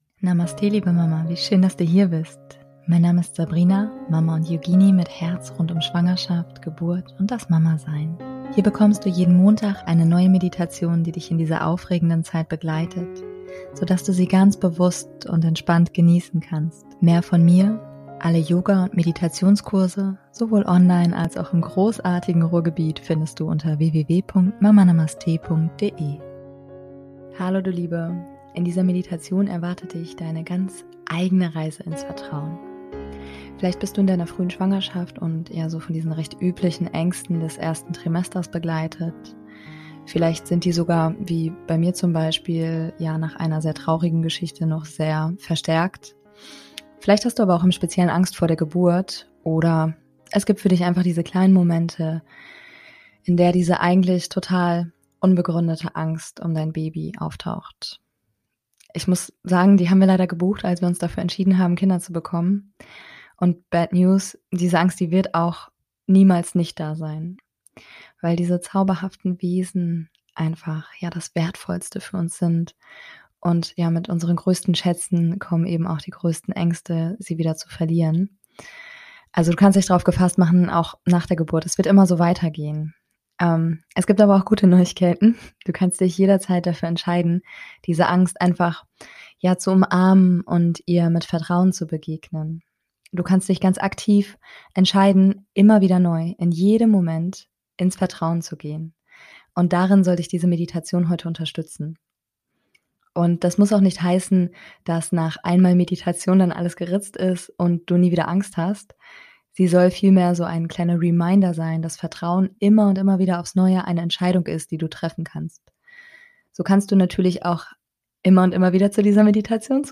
In dieser Meditation erwartet dich deine ganz eigene Reise ins Vertrauen.